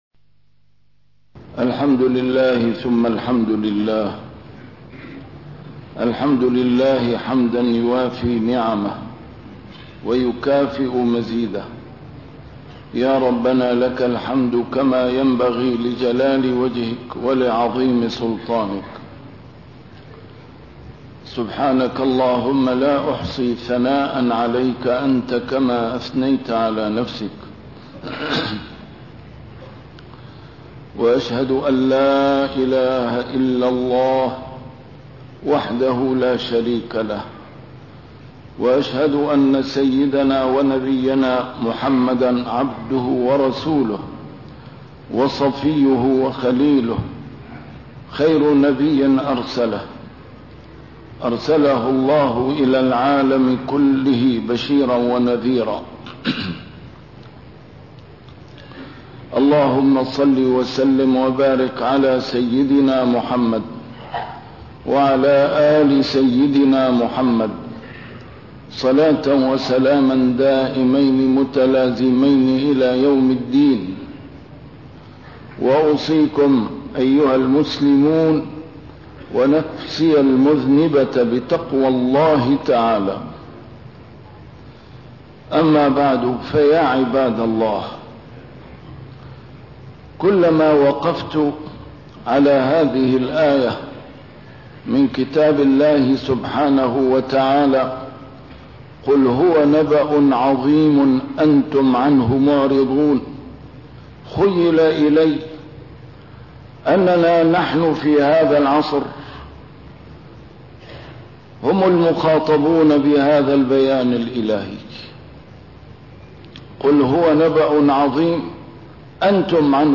نسيم الشام › A MARTYR SCHOLAR: IMAM MUHAMMAD SAEED RAMADAN AL-BOUTI - الخطب - قُلْ هُوَ نَبَأٌ عَظِيمٌ أَنْتُمْ عَنْهُ مُعْرِضُونَ